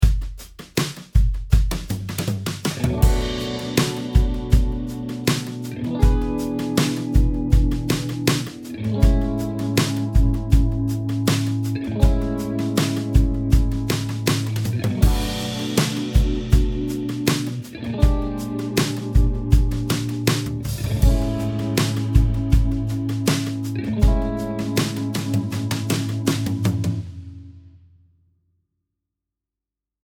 Not only does this wonderful substitution allow for a descending chromatic bassline from the Bmi7 chord down to the Ama7, but each of the tones in the B♭7 chord is a semitone away from each of the tones in the Ama7 chord, making its resolution to the Ama7 chord twice as strong as the original dominant E7 chord, with only two semitone resolutions: D to C#, and G# to A.
As shown below, you can also try using a B♭13 instead of a basic B♭7 chord.
Tritone-Substitution-Major-7-Resolution.mp3